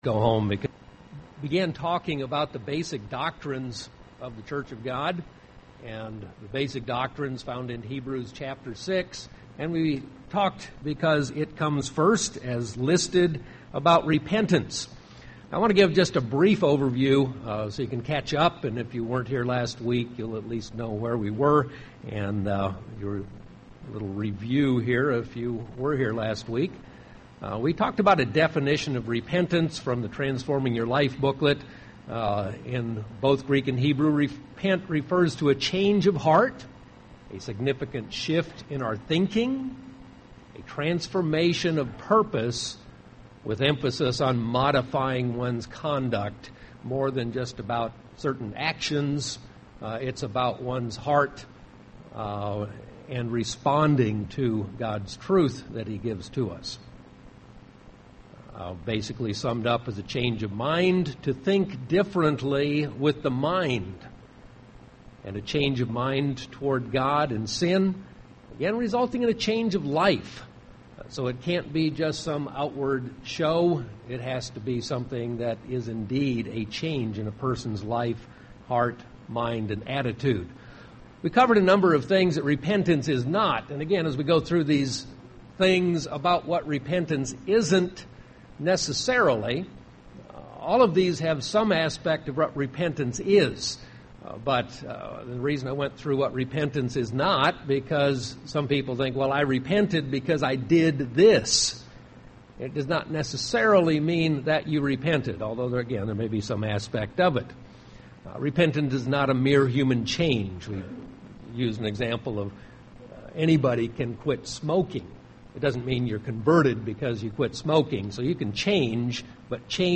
Given in Phoenix Northwest, AZ
UCG Sermon Studying the bible?